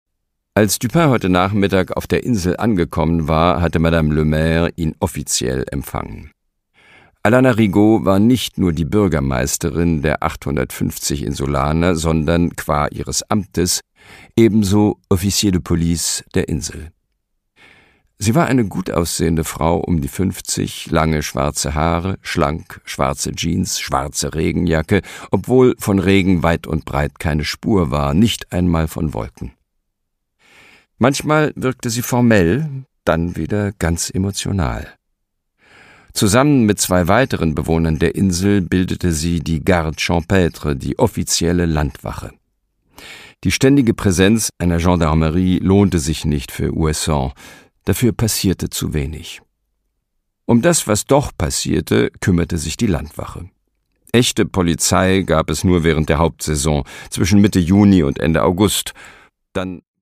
Produkttyp: Hörbuch-Download
Gelesen von: Christian Berkel